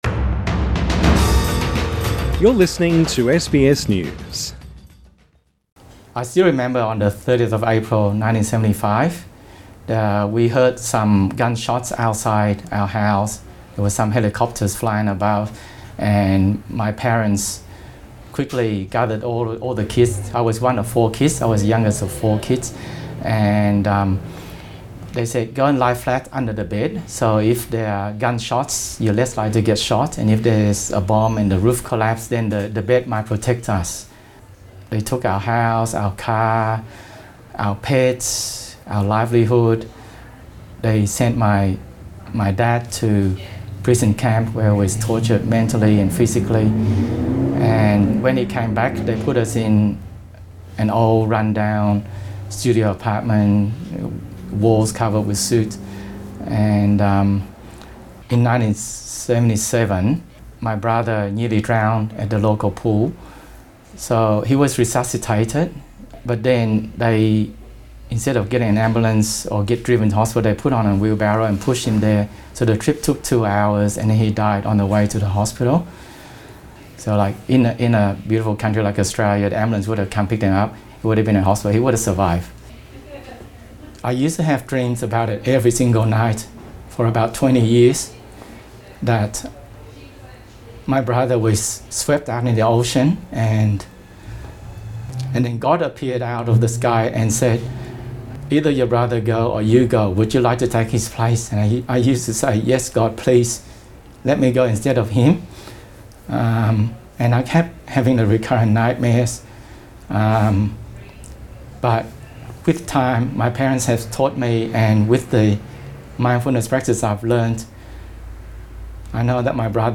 SBS News In Depth